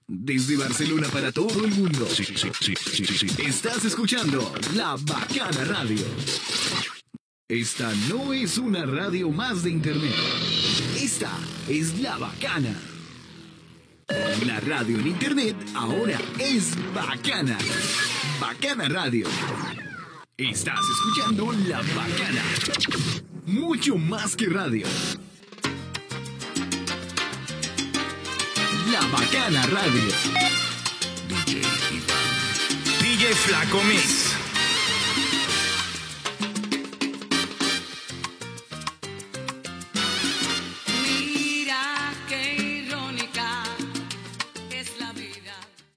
Identificació de la ràdio i del DJ, tema musical